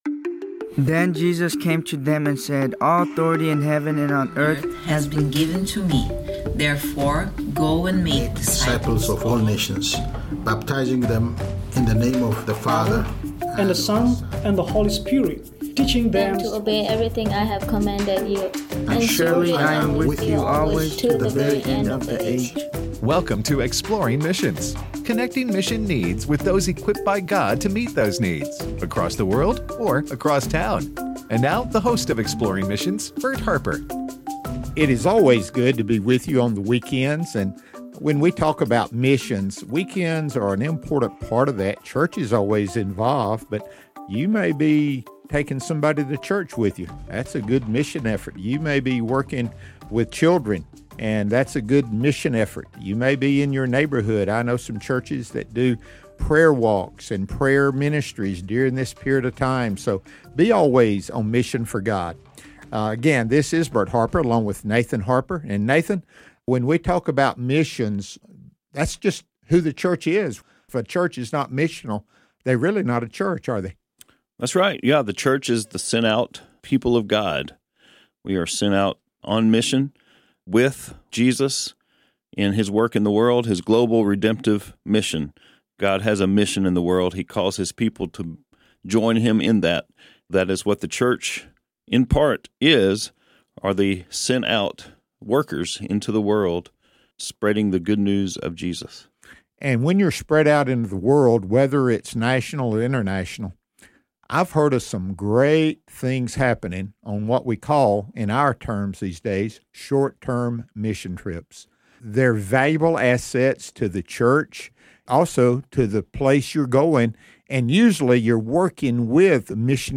The Bucket Ministry: A Conversation